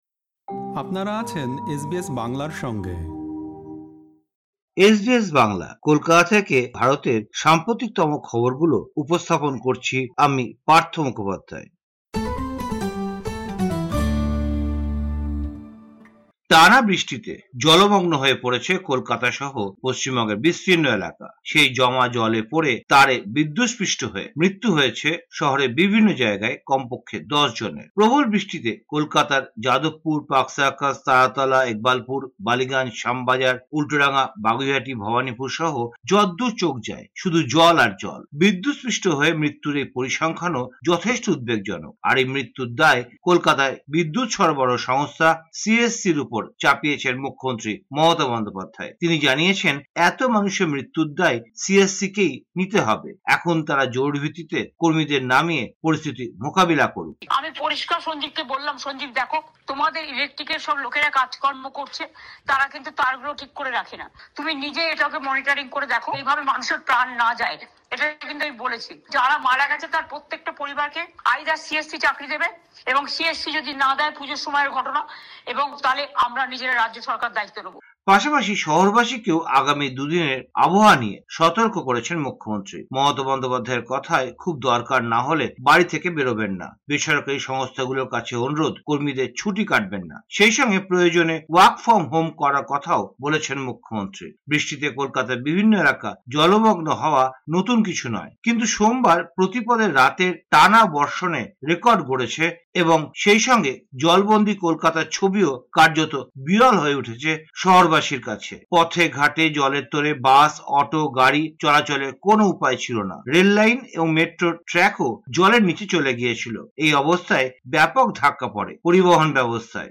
সম্পূর্ণ প্রতিবেদনটি শুনতে উপরের অডিও-প্লেয়ারে ক্লিক করুন।